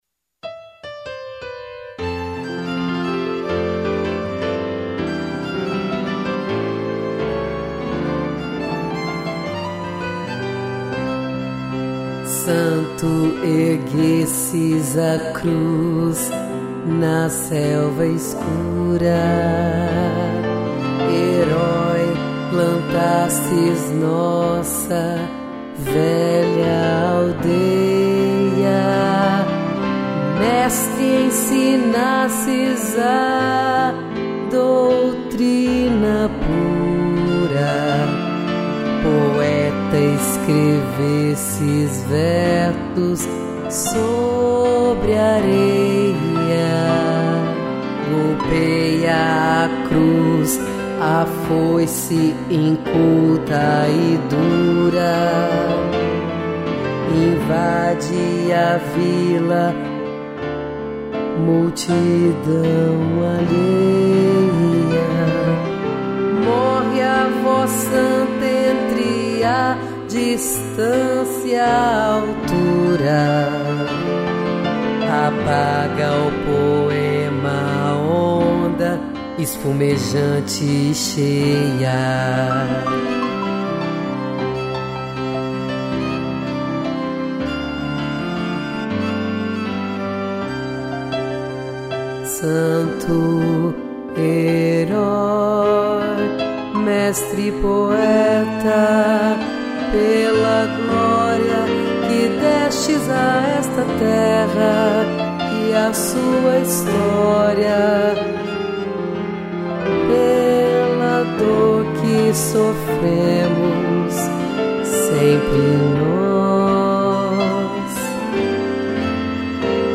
piano, cello e violino